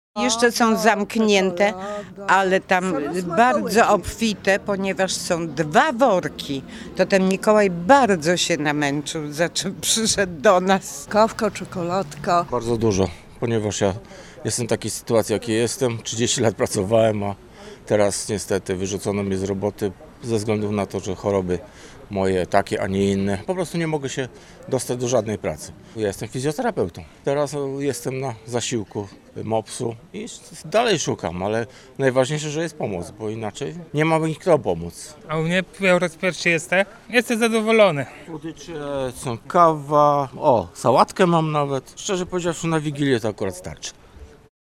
Pytamy mieszkańców i mieszkanki, jak oceniają zawartość podarunków.
sonda_paczki.mp3